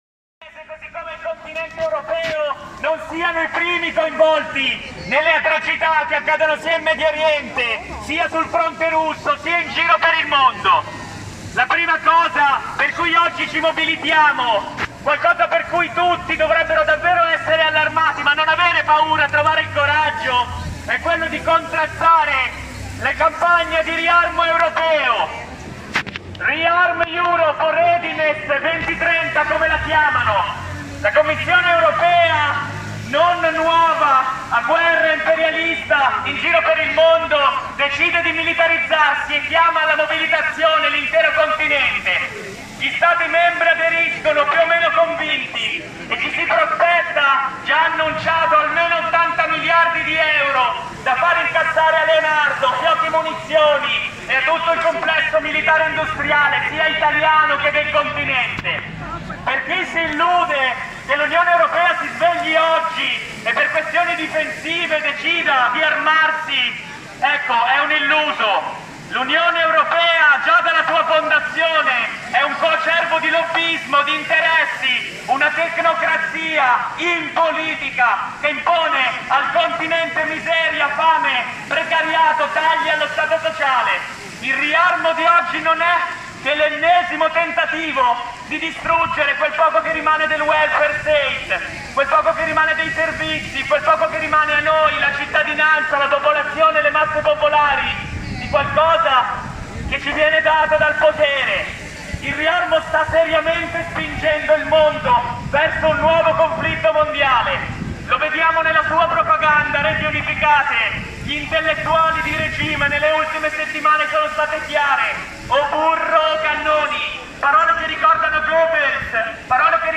Il corteo contro la guerra a Busto Arsizio
Una presenza determinata, chiassosa e solidale con la Resistenza Palestinese, contro il genocidio, la guerra e la NATO, in strada sabato scorso a Busto Arsizio.
Intervento di Miracolo Milano
Alcuni slogans gridati